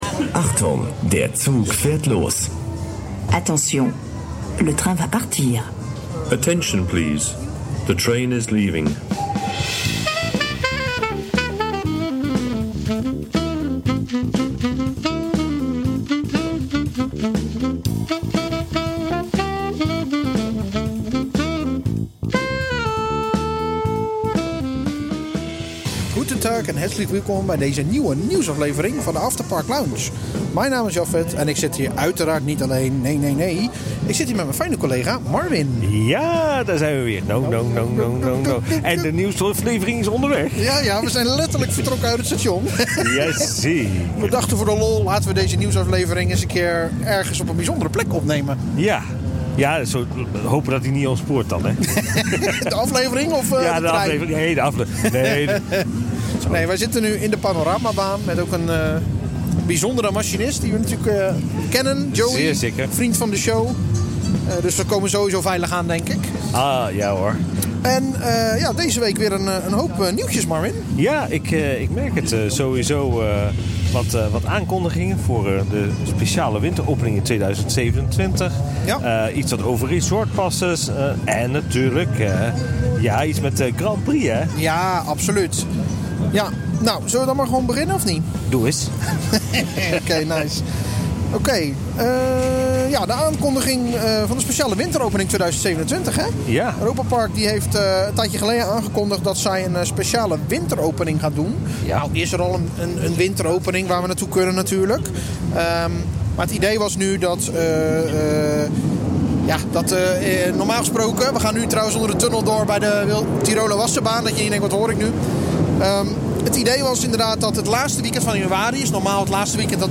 je letterlijk mee onderweg met het Europa-Park-treintje en bespreken ze ondertussen het allerlaatste parknieuws.